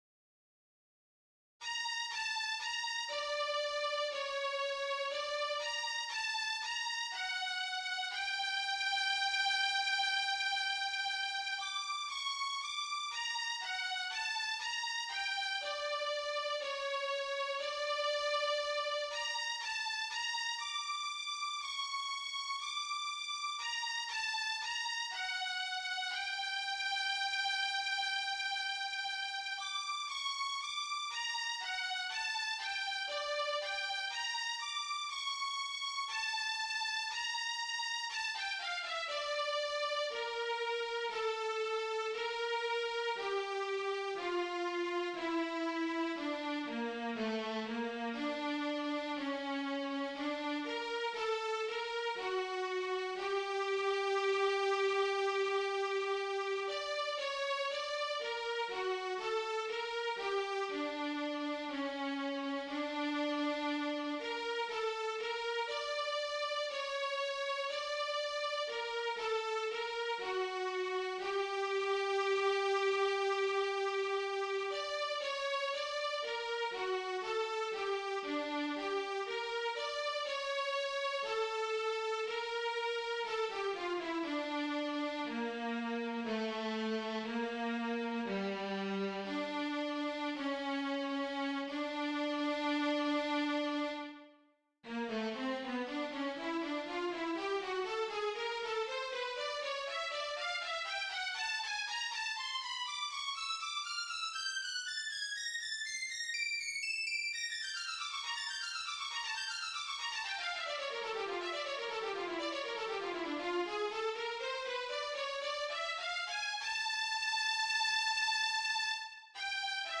original composition
Dark Flight, on the violin, though she played a piano version for us.
Dark-flight-violin.mp3